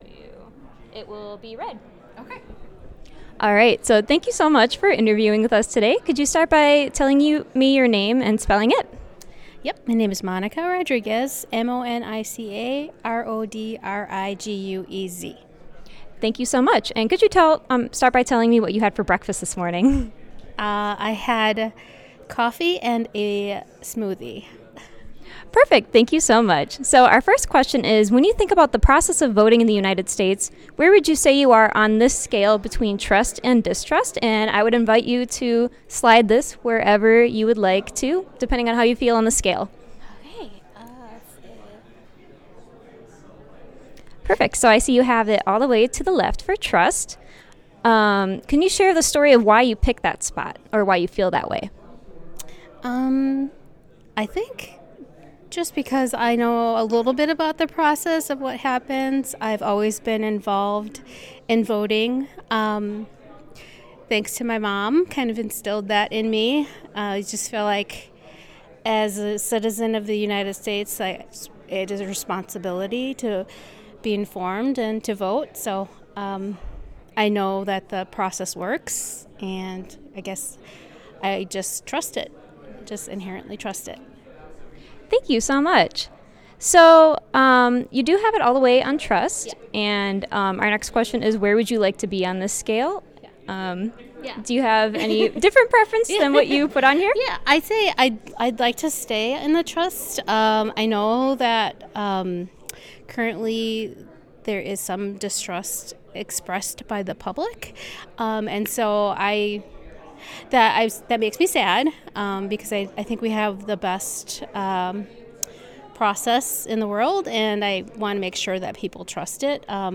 UWM at Waukesha